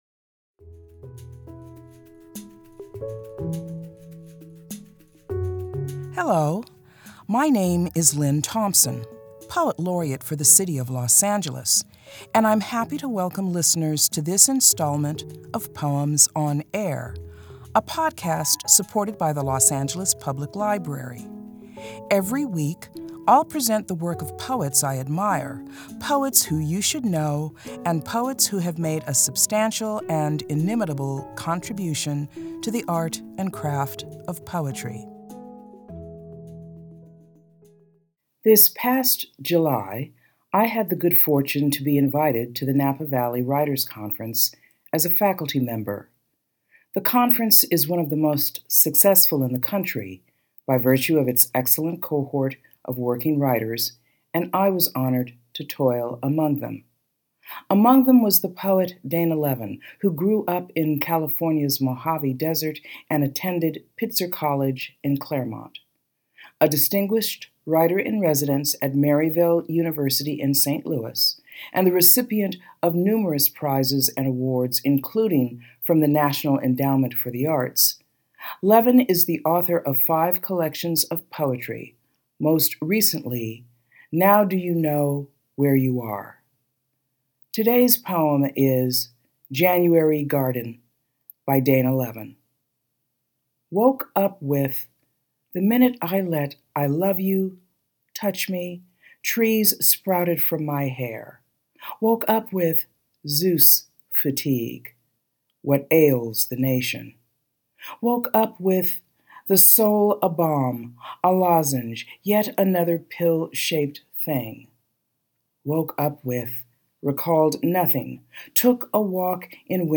Los Angeles Poet Laureate Lynne Thompson reads Dana Levin's poem "January Garden."